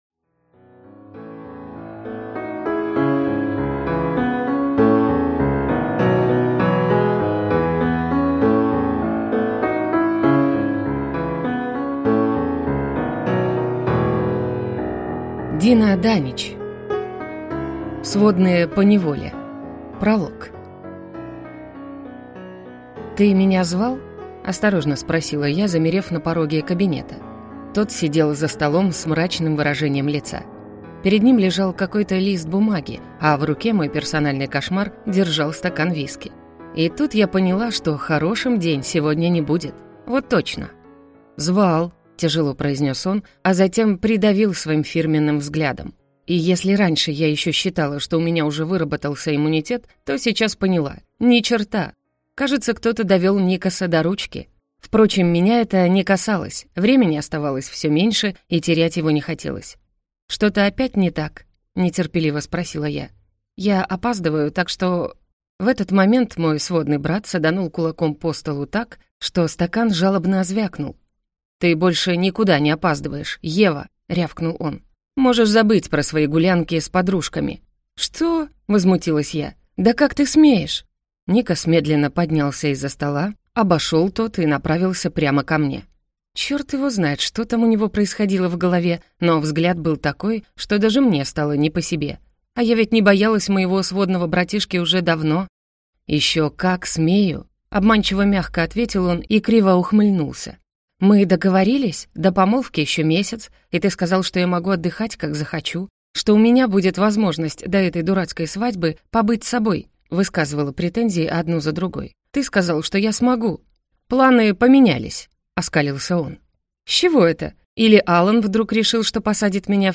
Аудиокнига Сводные поневоле | Библиотека аудиокниг